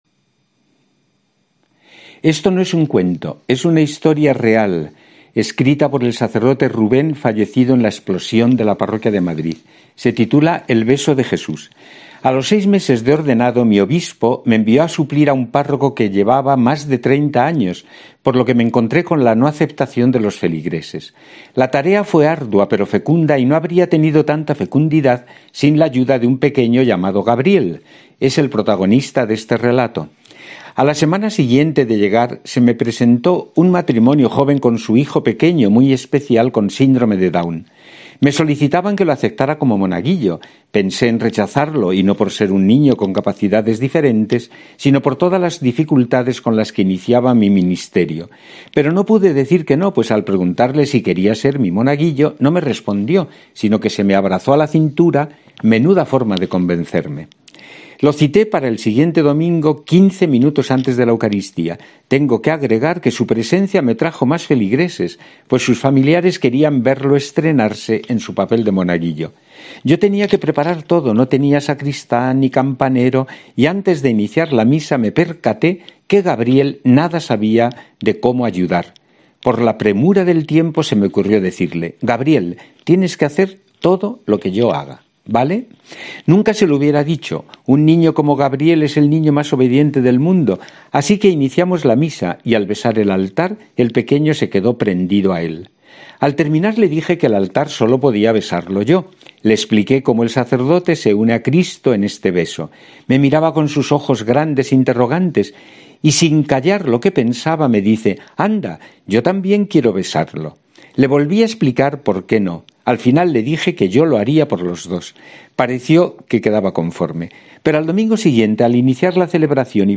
Cuento monseñor César Franco